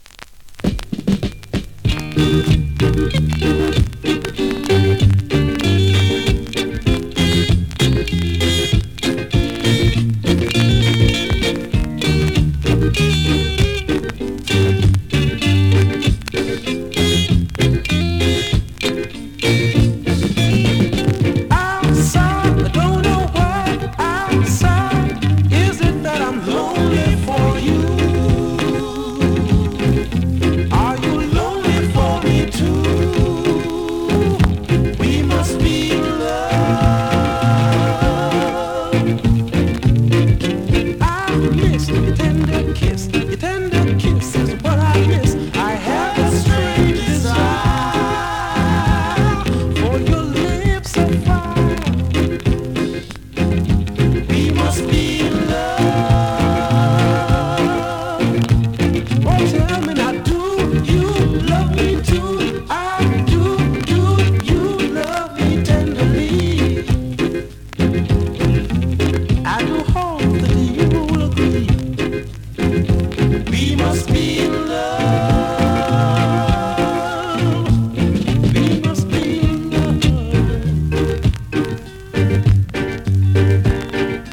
SKA〜REGGAE
スリキズ、ノイズそこそこありますが